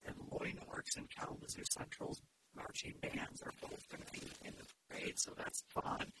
Kalamazoo Public Schools trustee Jennie Hill says they will also be well represented.